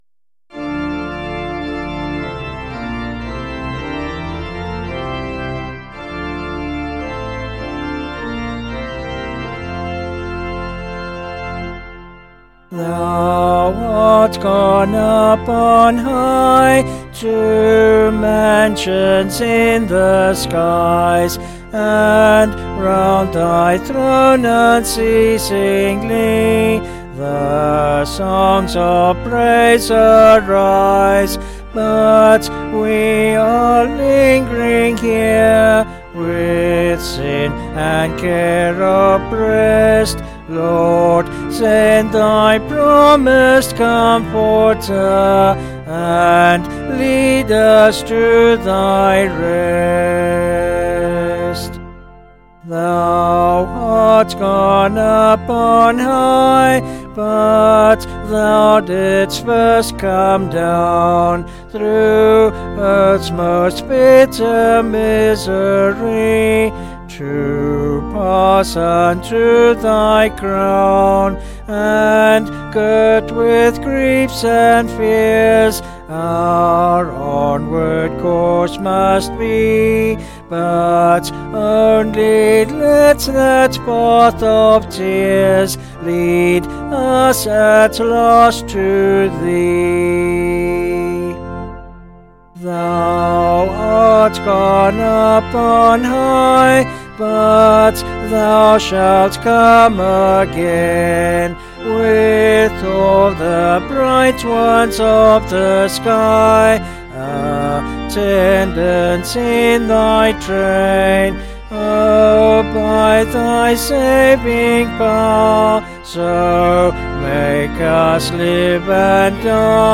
Vocals and Organ   701.4kb Sung Lyrics